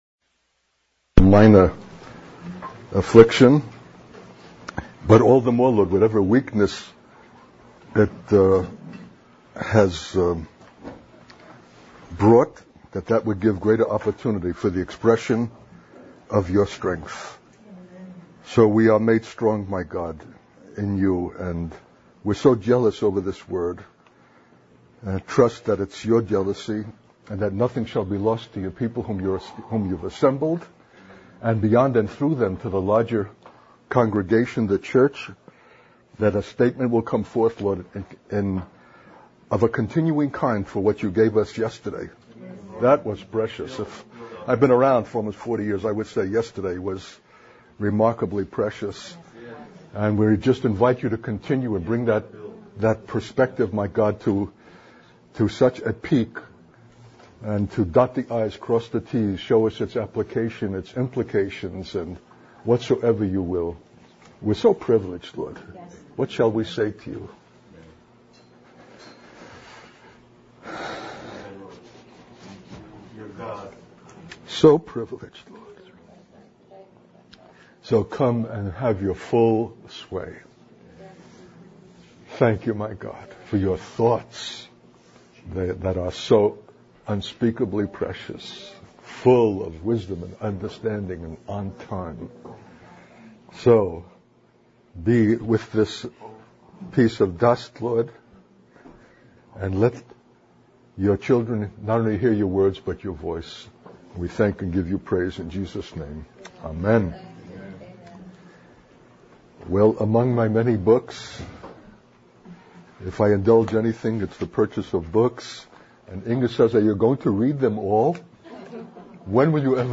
In this sermon, the speaker emphasizes the importance of rendering honor, obedience, and gratitude to fathers and those in positions of authority. He acknowledges that human nature often resists submission due to a desire for self-exaltation.